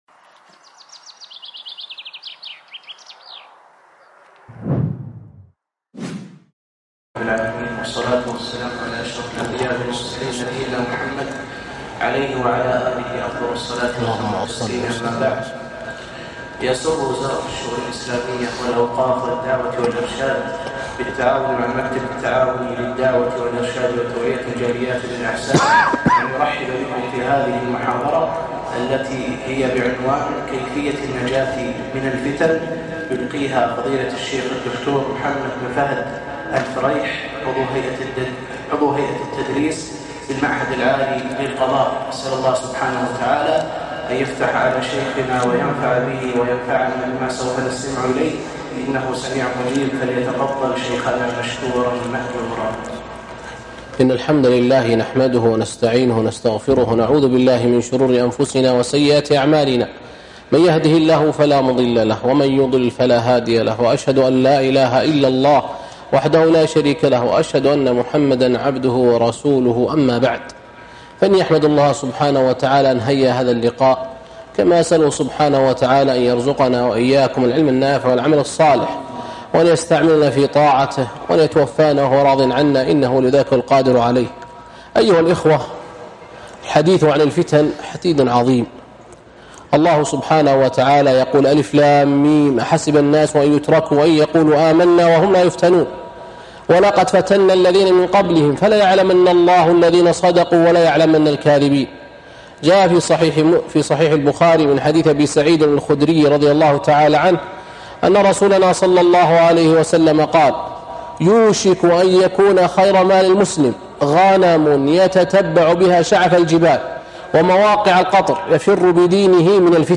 كيفية النجاة من الفتن - محاضرة